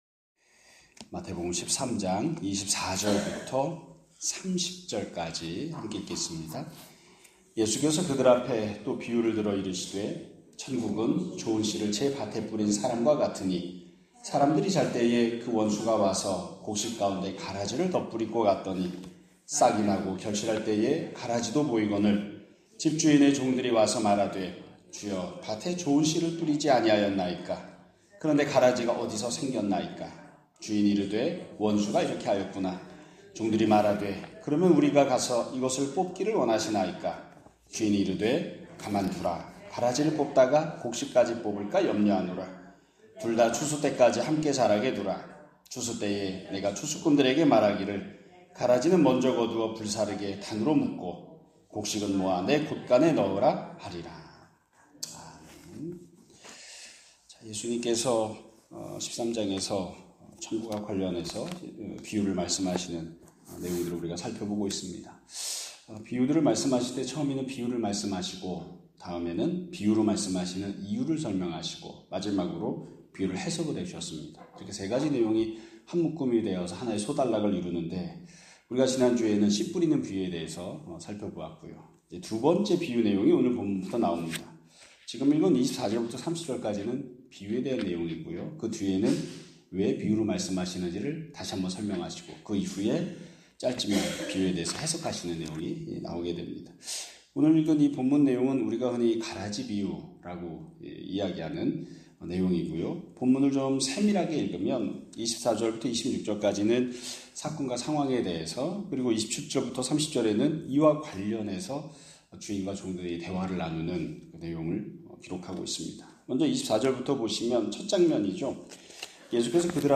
2025년 9월 29일 (월요일) <아침예배> 설교입니다.